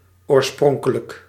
Ääntäminen
IPA: /i.ne.di/